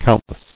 [count·less]